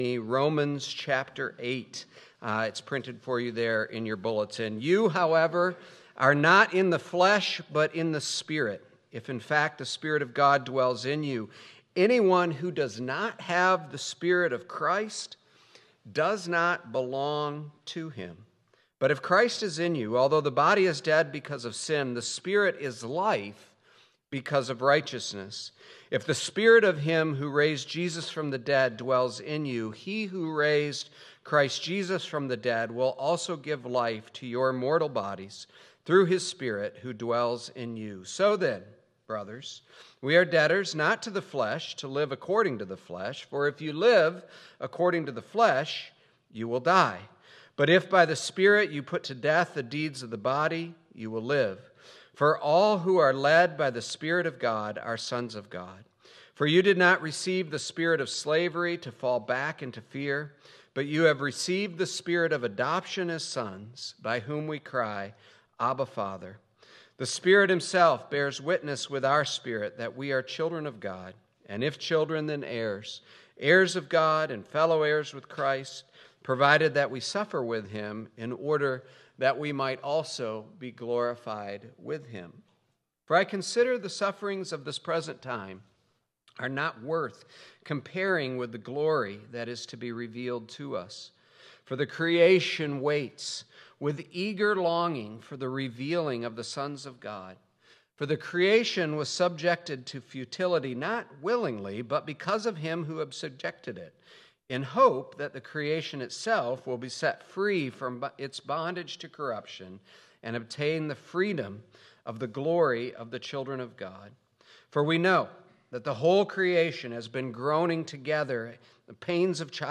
2.1.26 Sermon.m4a